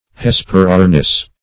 Search Result for " hesperornis" : The Collaborative International Dictionary of English v.0.48: Hesperornis \Hes`pe*ror"nis\, n. [NL., fr. Gr.